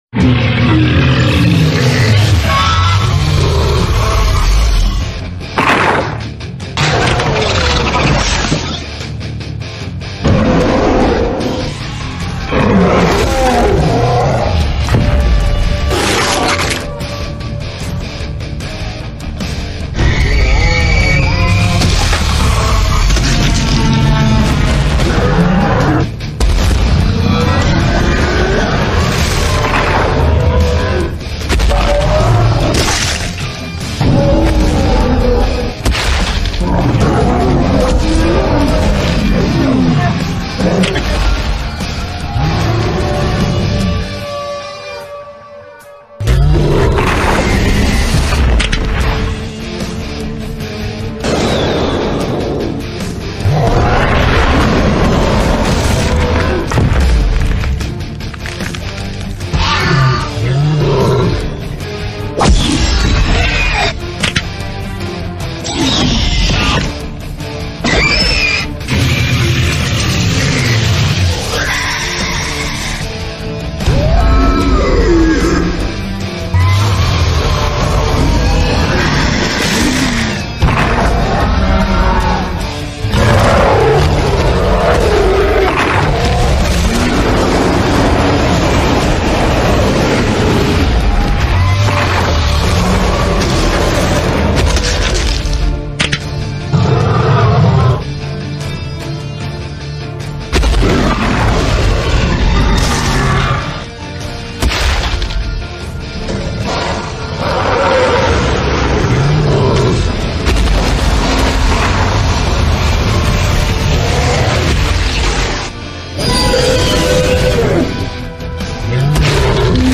savage dilophosaurus rampage sound effects free download